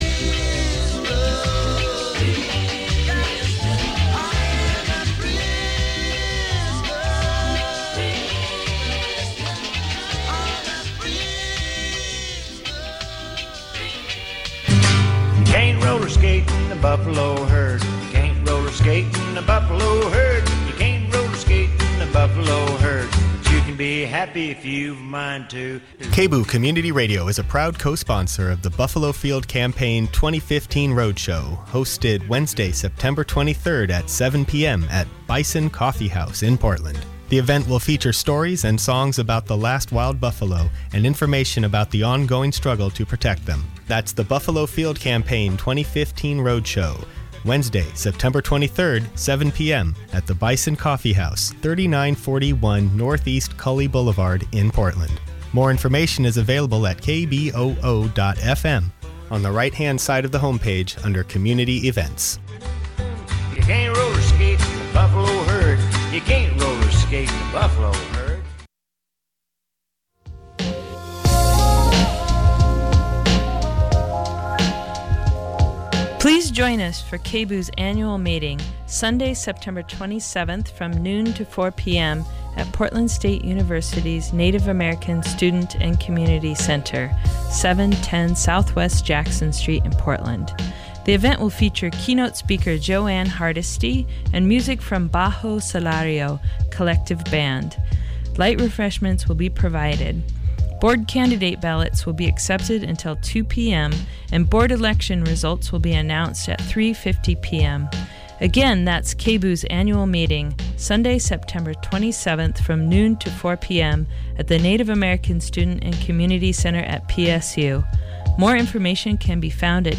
It'll feature live music performed on the boat's bow. We'll produce it in a teeny soundbooth wedged between the head and galley.
Download audio file Three friends float down the Mississippi, recording sounds and stories on their paddlewheel riverboat and broadcasting an original radio story along the way.